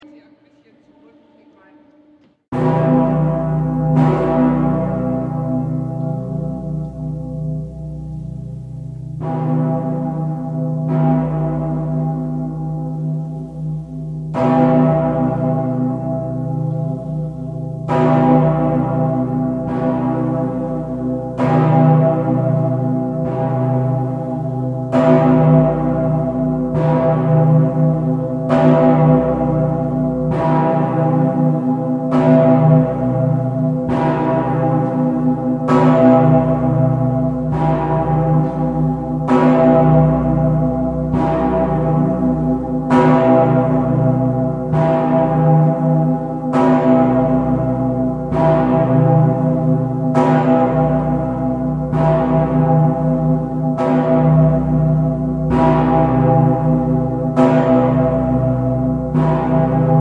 24톤의 '성 베드로의 종'(St. Petersglocke, 쾰른어로 "Decke Pitterksh" 또는 "Dicker Pitter")은 1922년에 주조되었으며[140], 진자 운동을 하는 서양식 종 중 세계에서 가장 큰 종이었다.[141]
성 페테르스글로케 소리
성 베드로의 종 (Dicker Pitter)124000kgC0하인리히 울리히, 아폴다1923